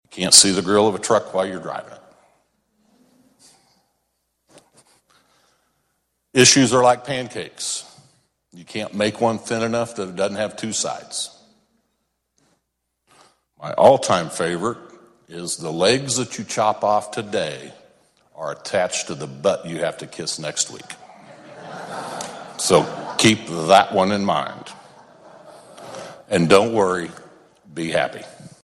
Jeff Longbine has represented the Senate’s 17th District the last 14 years. In his farewell speech, he left the body with some of his favorite sayings: